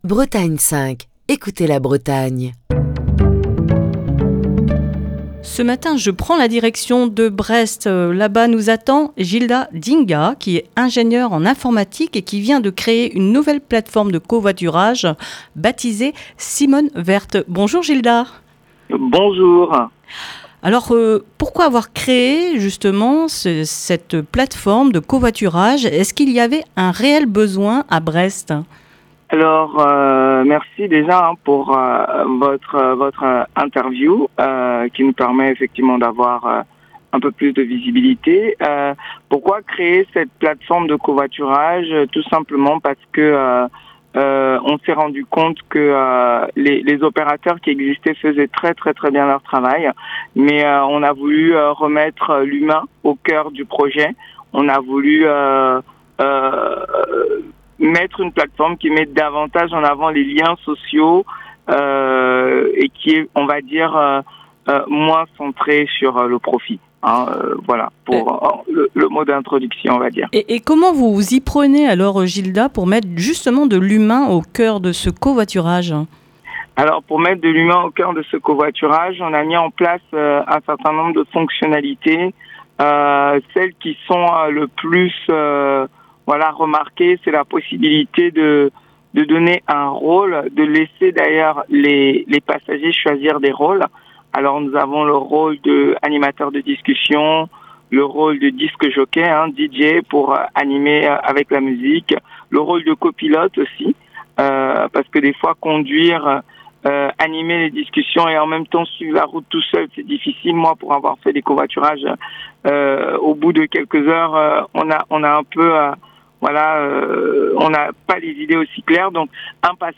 dans le coup de fil du matin